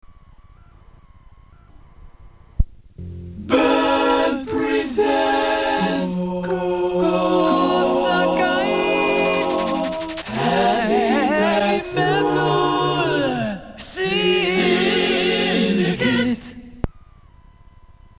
Radio ID
これが噂の多重録音ラジオID。